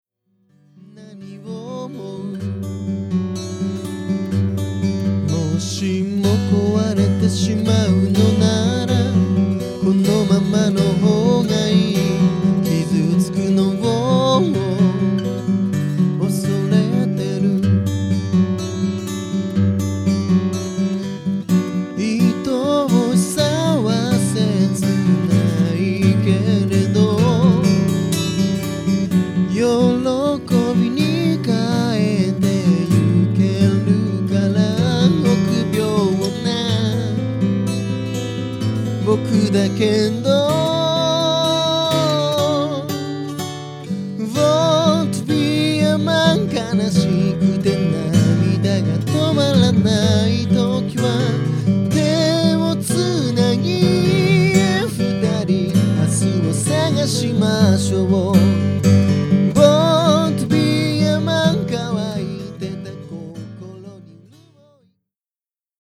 全体にそつがなくキャリアを感じさせる曲だけど、いまひとつメロディにフックがない。
でもアコギうまい。
基礎がしっかりしていて、たいへん安心して聞けるのですが、安全牌という感じで新鮮さに欠ける傾向が あります。